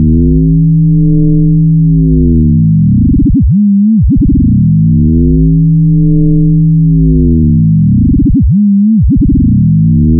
AM/FM synthesizer
Bizarre FM sound